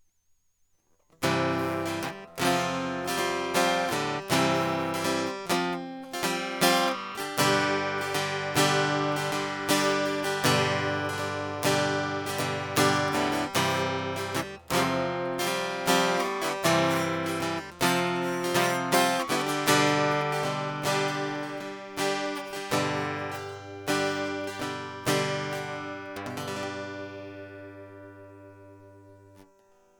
Guitar
I used a standard mono guitar cable to connect to my Line 6 Spider amplifier.
c-csus4-progression-line-out.mp3